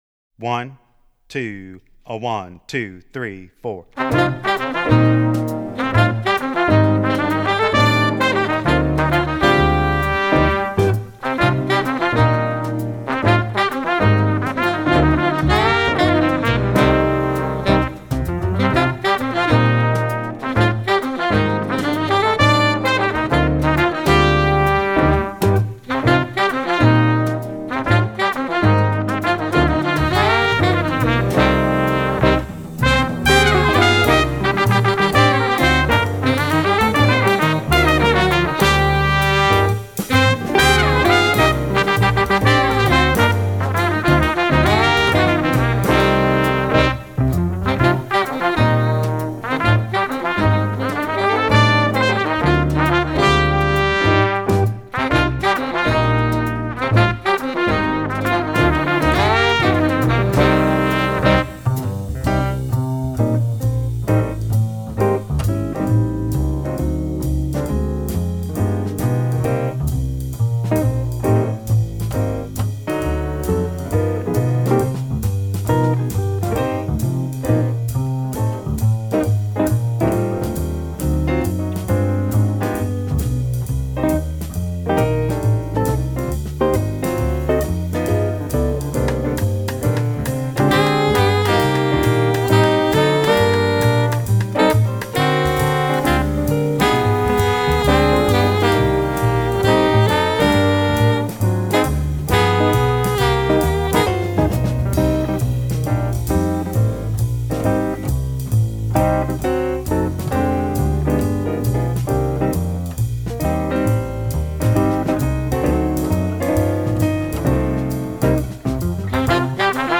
Voicing: Combo w/ Audio